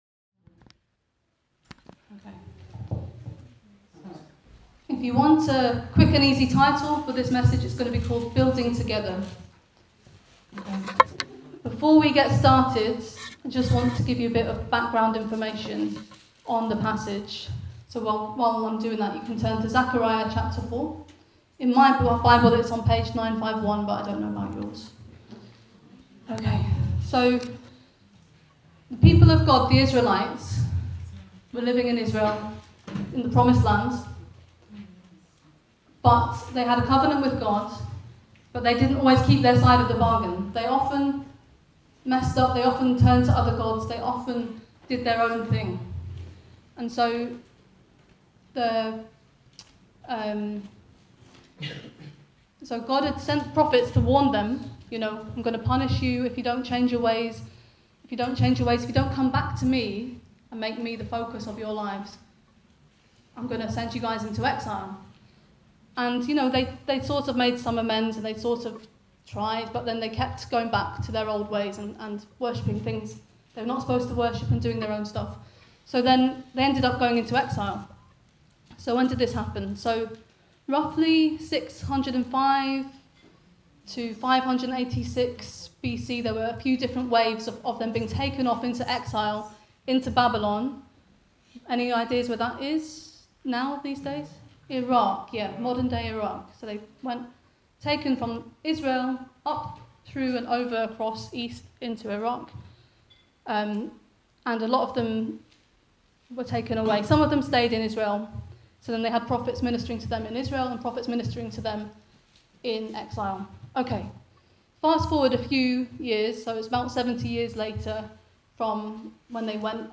Weekly message from The King’s Church.